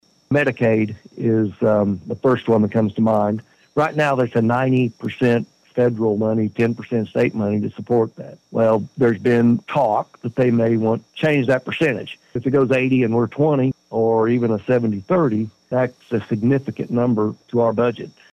Speaking with Representative Kane by phone, he said, "The Speaker of the House decides who he's going to include in that delegation to go and visit the White House and talk about how their policies, what their initiatives are, and how they may affect our states.This time, he offered this pretty much to the entire House, and it was a first-come, first-served."